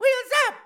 Voice clip from Mario Kart 8
MK8_Mario_-_Wheels_Up.oga.mp3